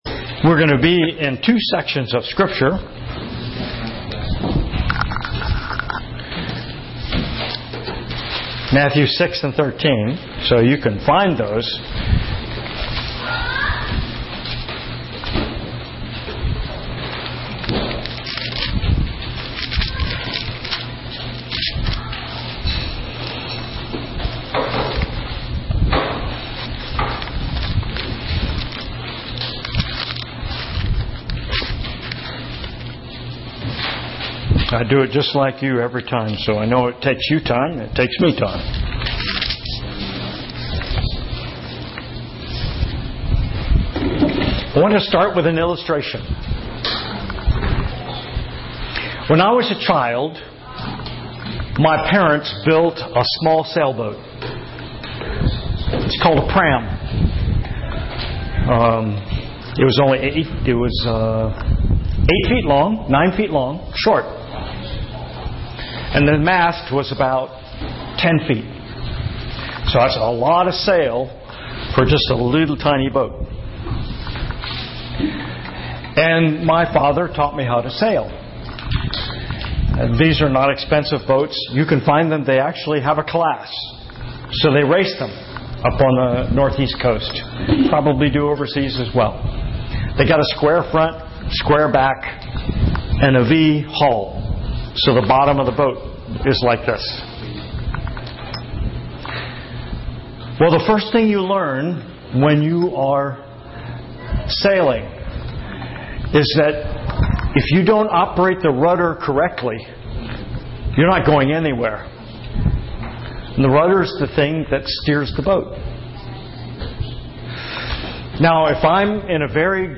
英文信息: 對神的態度、禱告、比喻